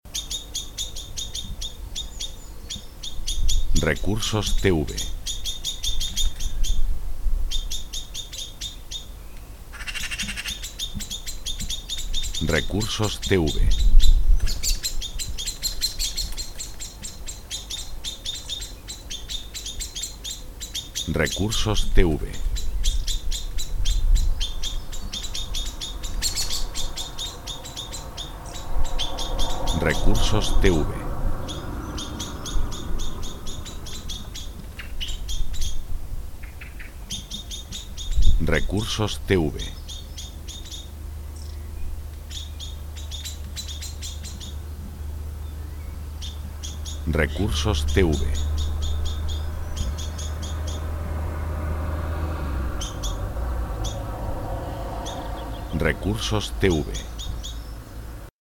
Efecto de pájaros cantando y coche pasando
birds_songs_and_cars_passing_by.mp3